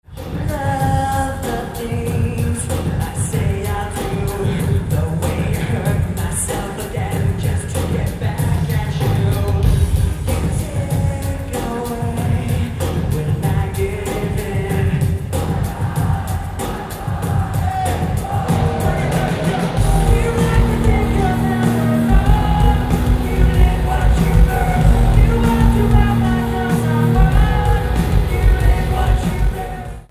• Location: Upper balcony, block L, row 1, left side.